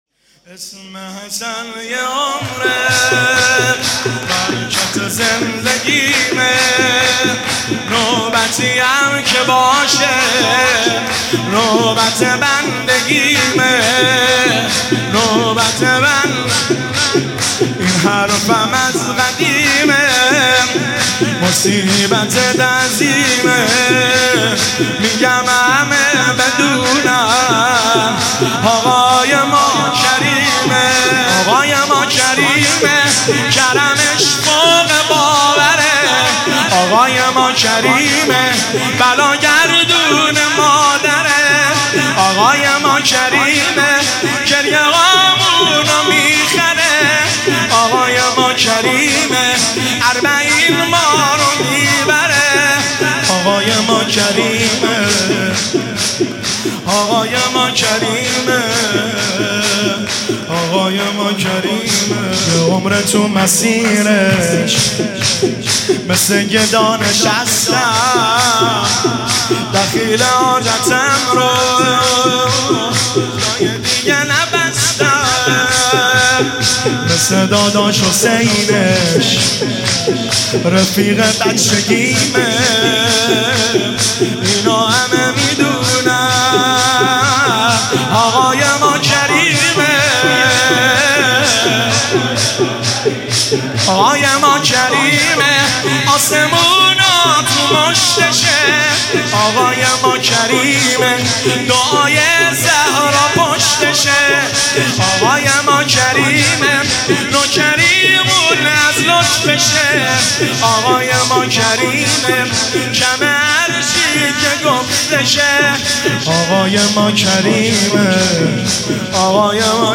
میلاد امام حسن مجتبی (ع)